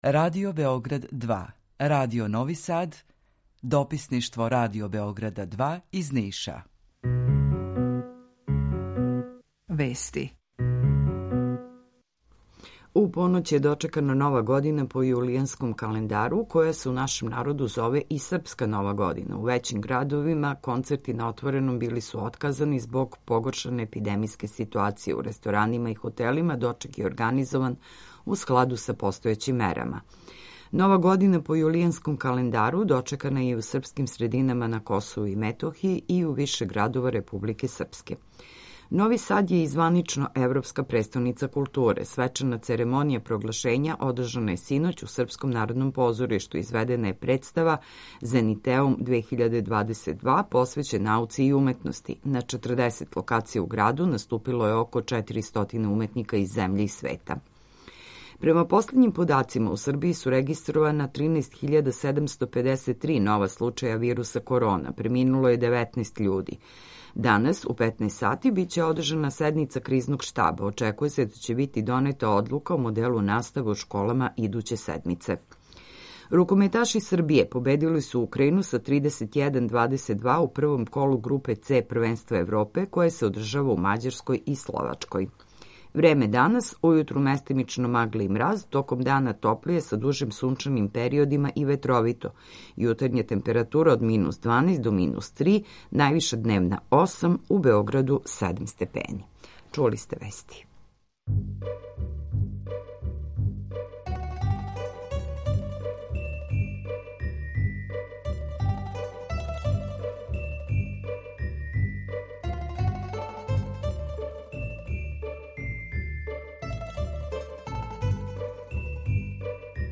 Емисију реализујемо заједно са студијом Радија Републике Српске у Бањалуци и Радијом Нови Сад
Јутарњи програм из три студија
У два сата, ту је и добра музика, другачија у односу на остале радио-станице.